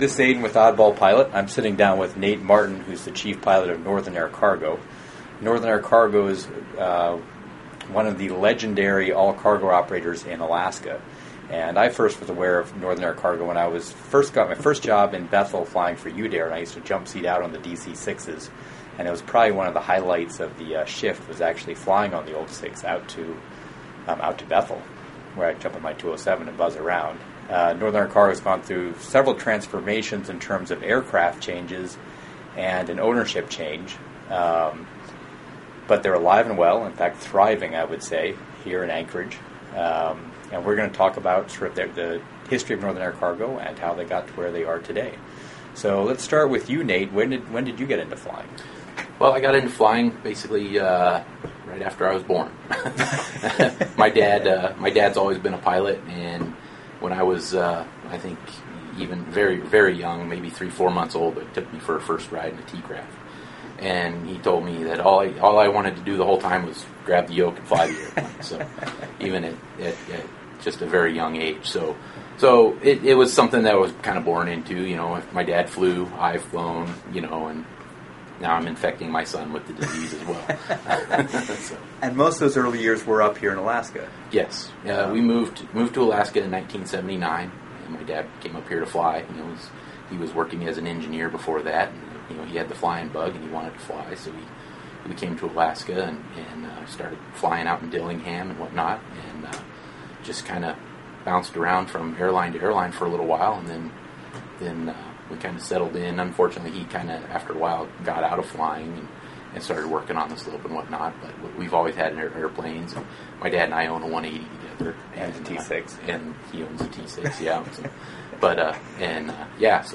In this interview you’ll hear about: